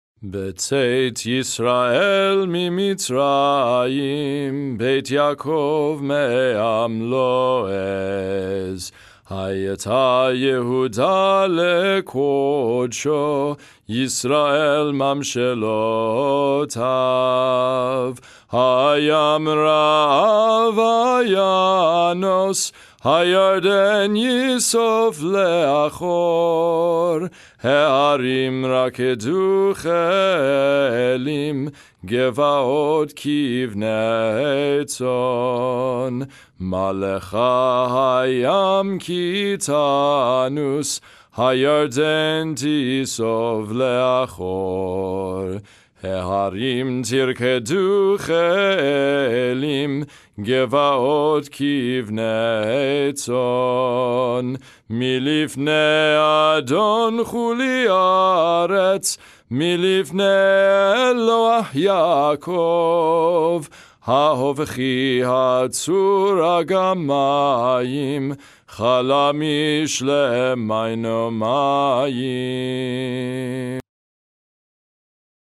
Festival Shaharit (Lower Voice)
Psalm 114 (Congregational Melody)Download
02_psalm_114__congregational_melody_.mp3